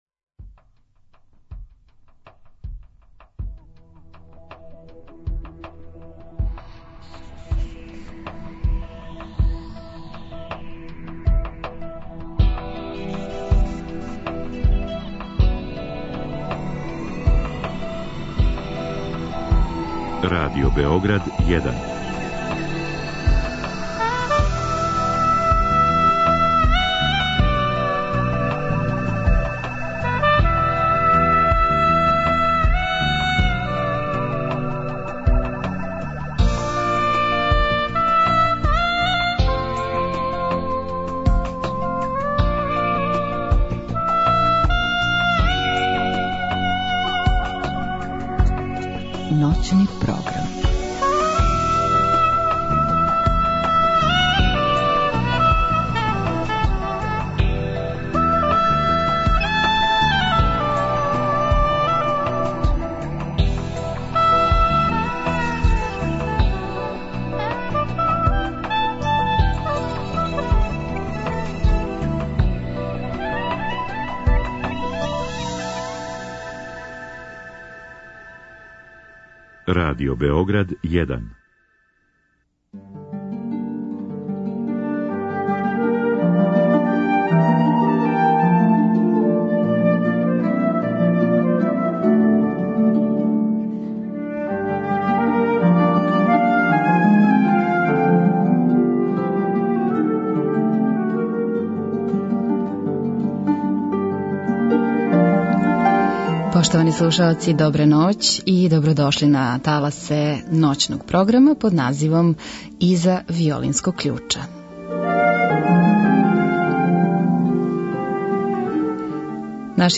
Од другог часа после поноћи уживаћемо у одабраним делима Брамса, Свиридова, Равела, Вагнера, Албениза и Родрига.